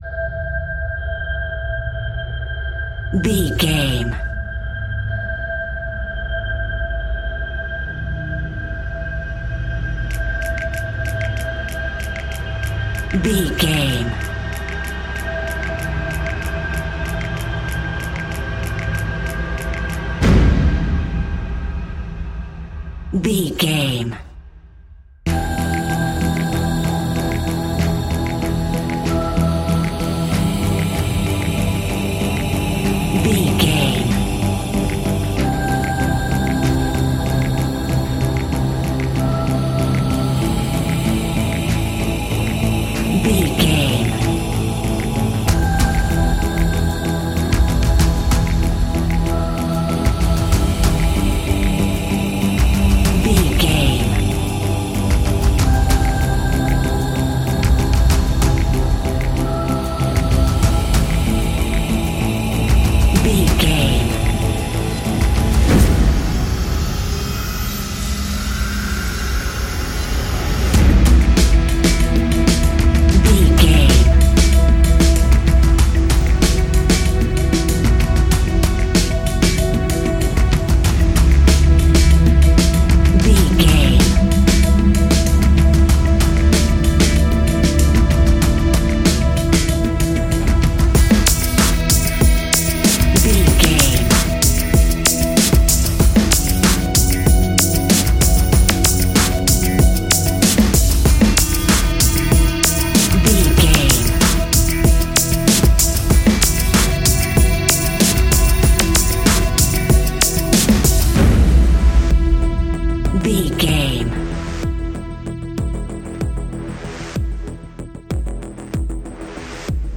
Epic / Action
Fast paced
In-crescendo
Ionian/Major
industrial
dark ambient
EBM
synths
Krautrock